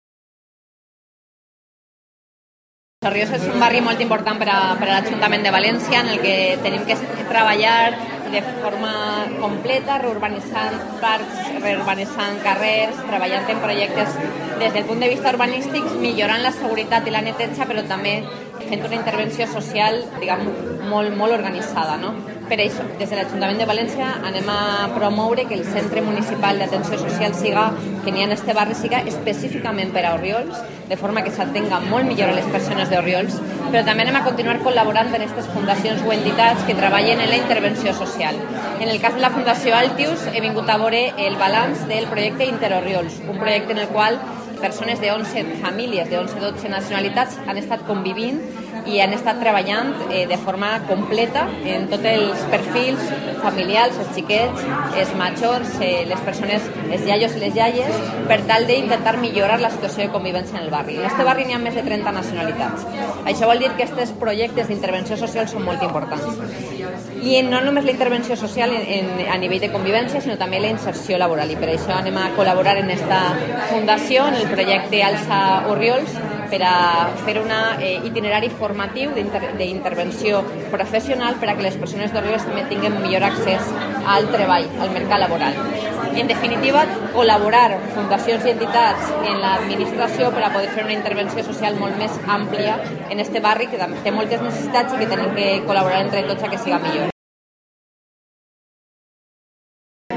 La alcaldesa de Valencia, María José Catalá, ha asistido este jueves a la presentación de los resultados del programa Inter Orriols, llevado a cabo por la Fundación Altius, y cuyo objetivo es mejorar el nivel de convivencia y la cohesión social, intercultural e intergeneracional entre la población del barrio para hacer del mismo un entorno inclusivo, acogedor, cálido, amable y seguro. Durante su intervención, la alcaldesa ha anunciado que el Ayuntamiento colaborará con el programa Alça Orriols para ayudar a la inclusión laboral de las personas más vulnerables de la zona.